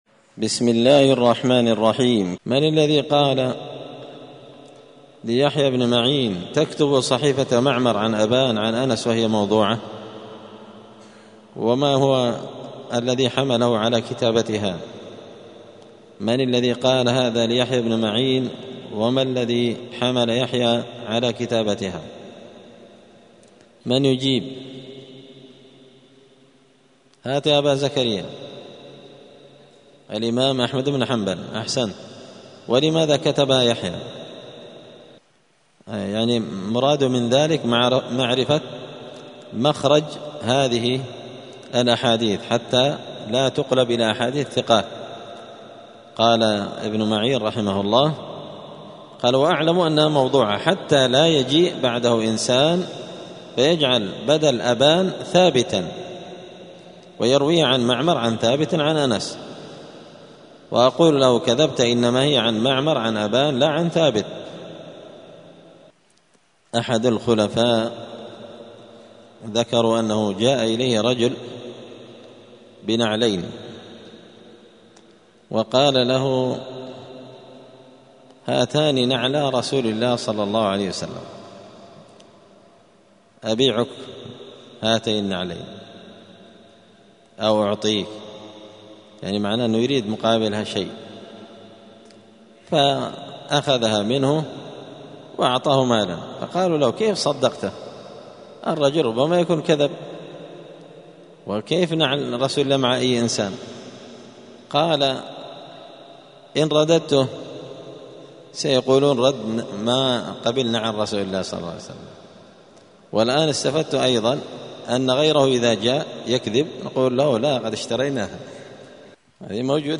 دار الحديث السلفية بمسجد الفرقان بقشن المهرة اليمن
الثلاثاء 24 محرم 1446 هــــ | الدروس، المحرر في الجرح والتعديل، دروس الحديث وعلومه | شارك بتعليقك | 29 المشاهدات